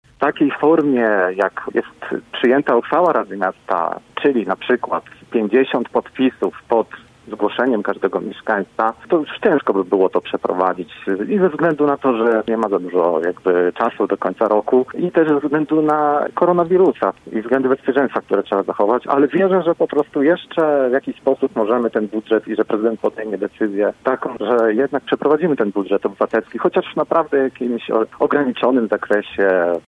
Radny Paweł Zalewski był gościem Rozmowy po 9.